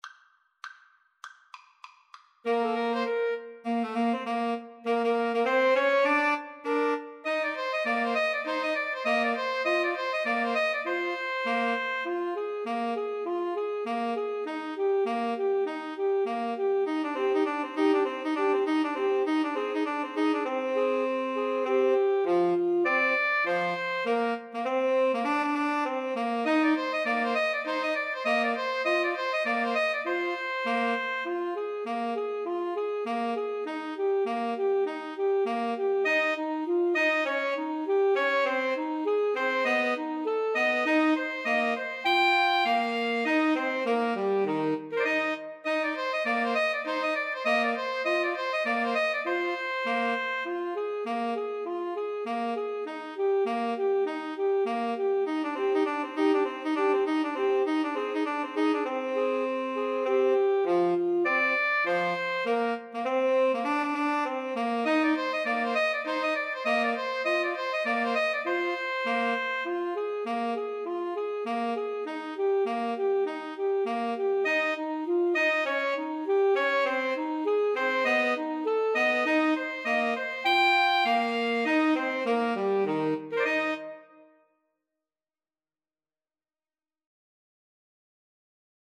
Alto Saxophone 1Alto Saxophone 2Tenor Saxophone
Eb major (Sounding Pitch) Bb major (French Horn in F) (View more Eb major Music for 2-Altos-Tenor-Sax )
Jazz (View more Jazz 2-Altos-Tenor-Sax Music)